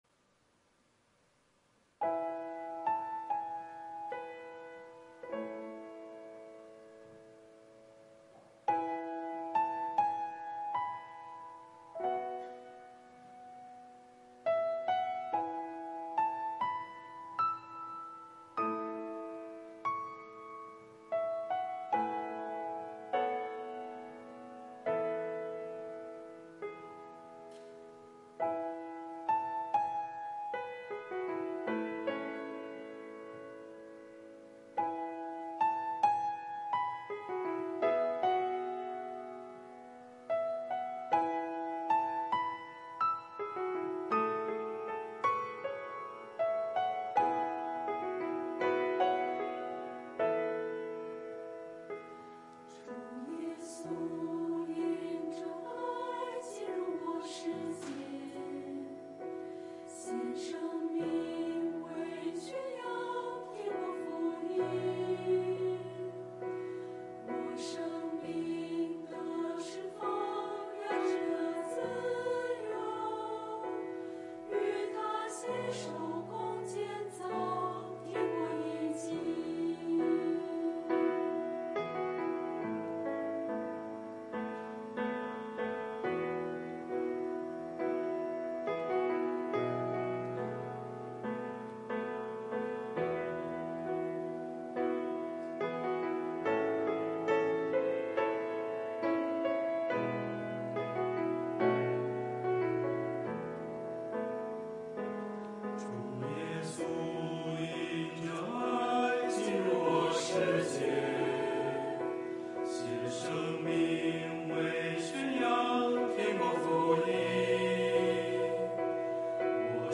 团契名称: 青年、迦密诗班 新闻分类: 诗班献诗 音频: 下载证道音频 (如果无法下载请右键点击链接选择"另存为") 视频: 下载此视频 (如果无法下载请右键点击链接选择"另存为")